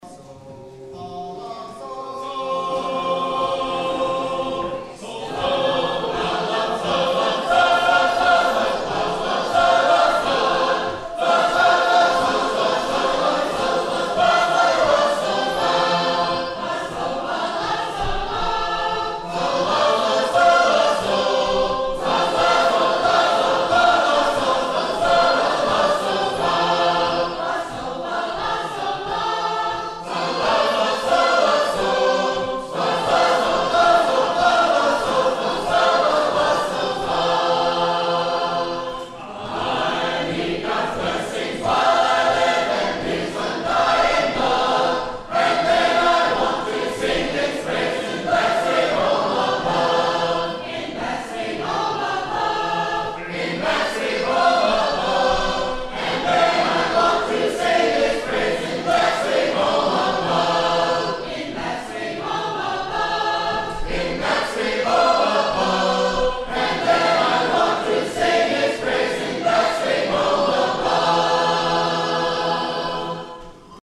United Convention – September 9-10, 2017 | Southern Field Recordings
114th Session – September 9-10, 2017